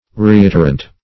Search Result for " reiterant" : The Collaborative International Dictionary of English v.0.48: Reiterant \Re*it"er*ant\ (r?-?t"?r-ant), a. [See Reiterate .]